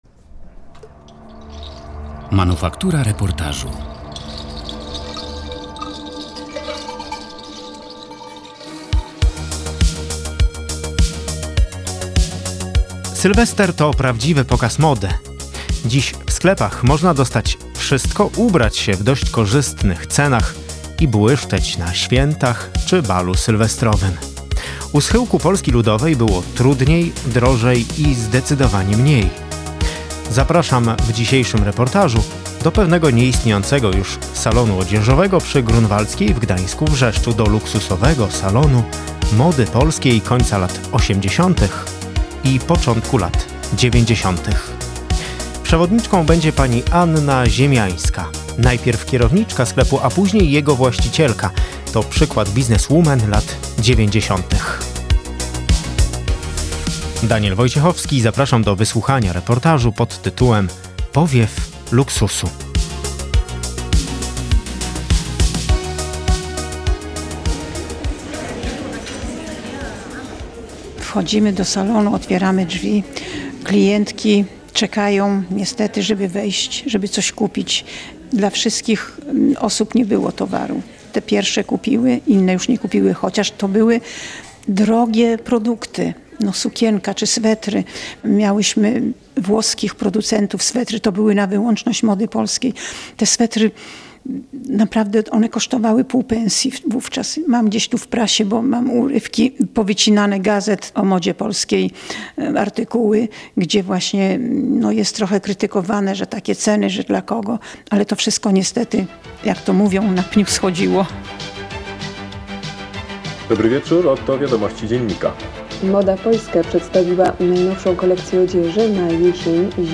O luksusie, o rzeczach osiągalnych tylko dla niewielu i sylwestrach sprzed lat. Reportaż „Powiew Luksusu”